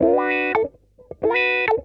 OUEEDA SLIDE.wav